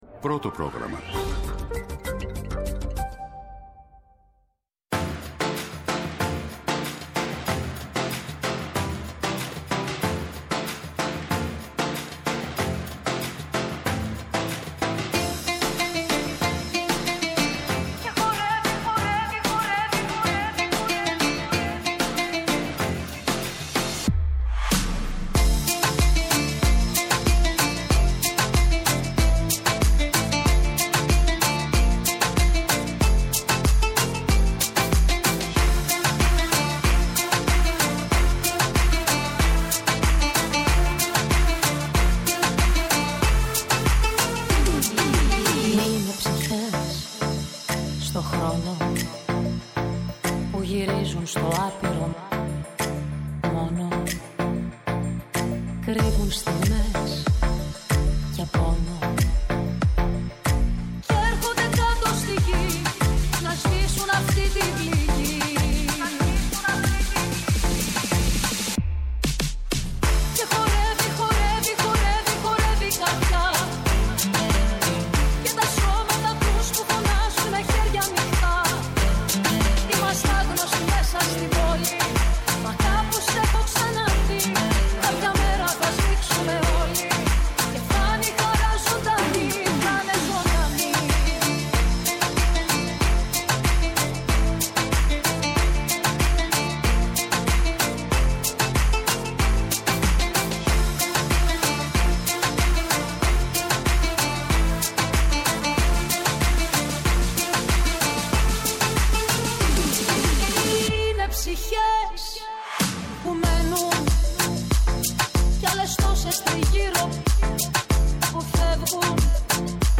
Τα θέματα που μας απασχόλησαν, μέσα από ηχητικά αποσπάσματα, αλλά και συνεντεύξεις.